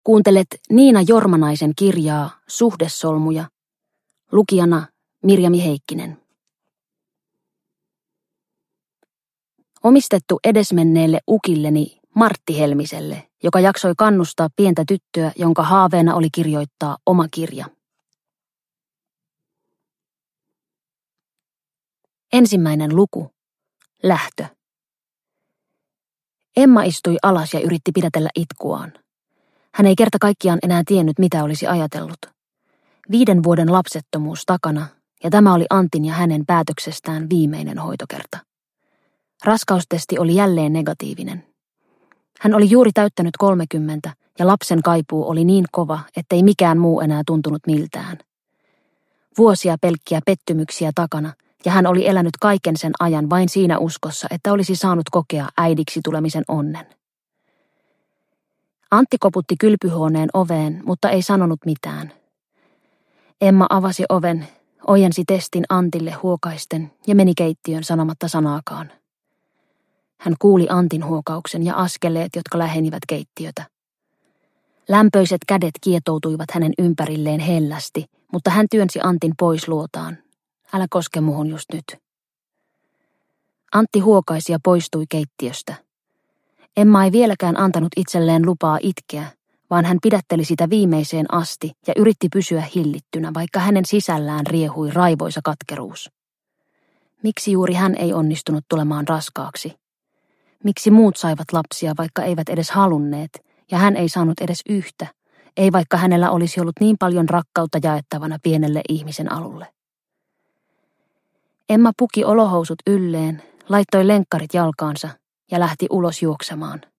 Suhdesolmuja – Ljudbok – Laddas ner